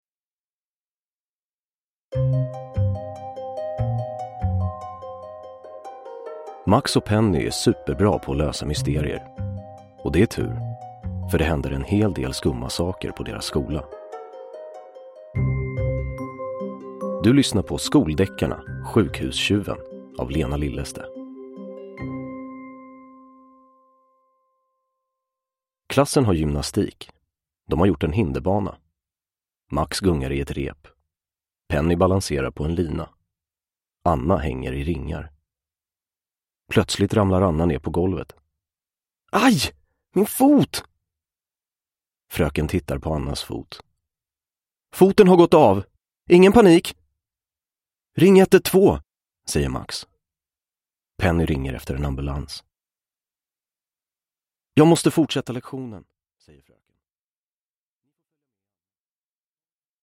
Sjukhus-tjuven – Ljudbok
Uppläsare: Anastasios Soulis